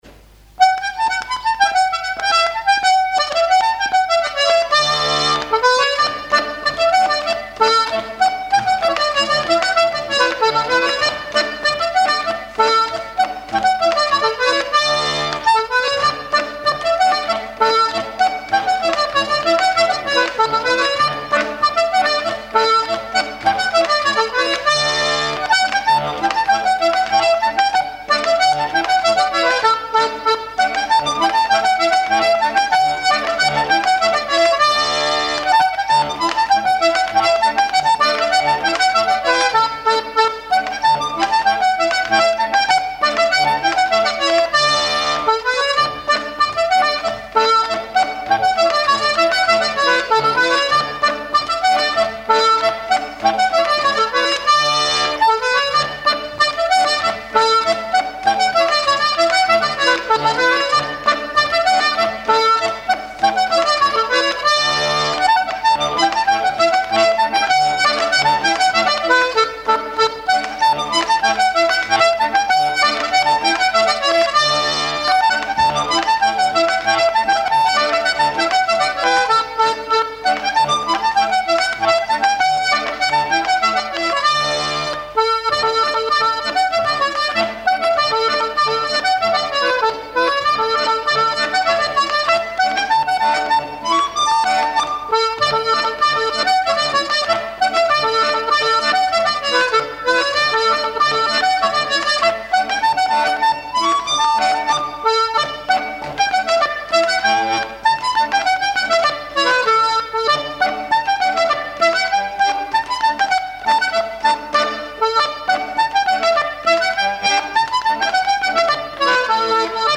Enchaînement de quatre pas d'été (version lente)
danse : pas d'été
airs de danse à l'accordéon diatonique
Pièce musicale inédite